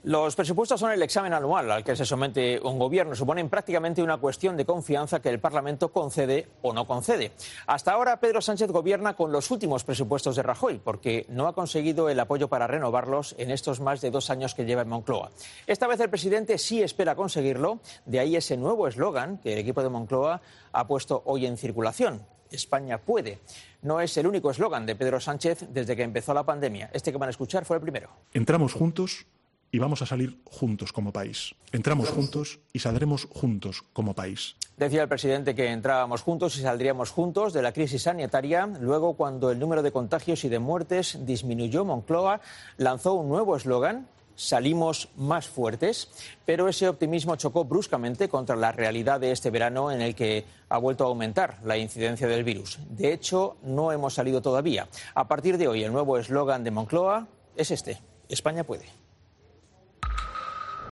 El presentador Vicente Vallés ha cargado contra la propaganda dirigida por Iván Redondo y el doble discurso de Podemos con el Ibex